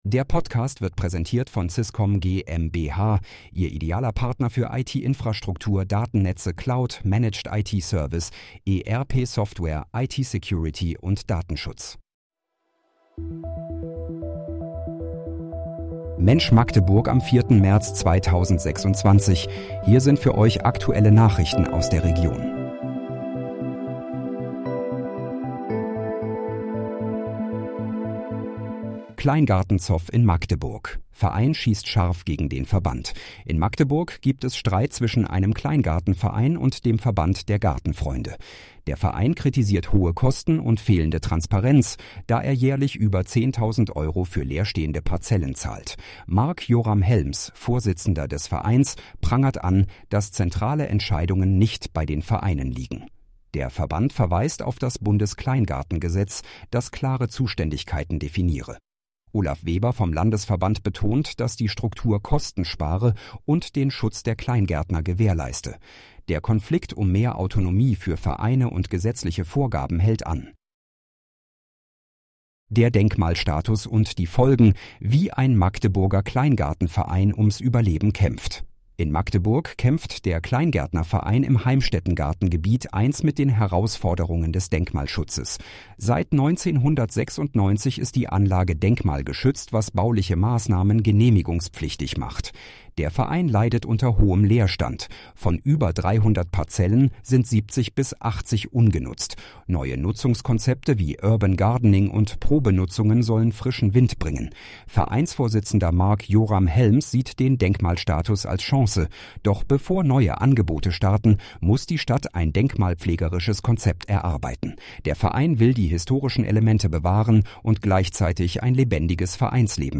Mensch, Magdeburg: Aktuelle Nachrichten vom 04.03.2026, erstellt mit KI-Unterstützung